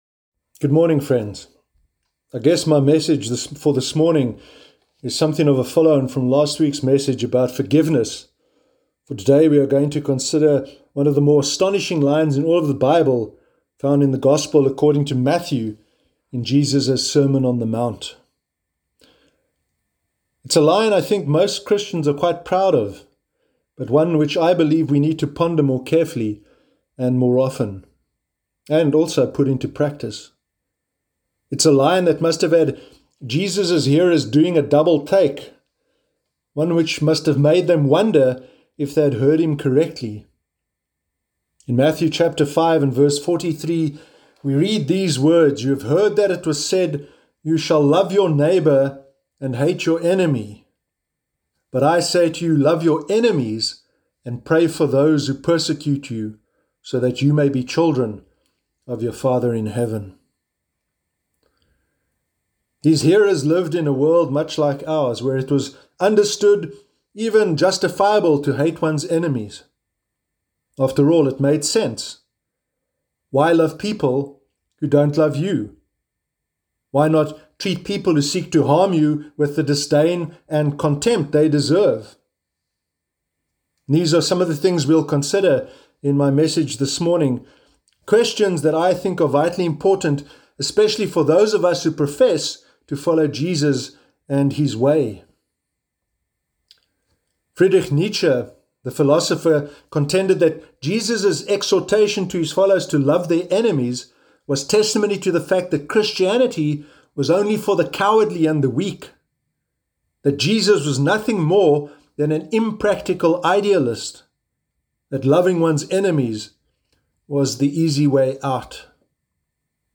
Sermon Sunday 19 July 2020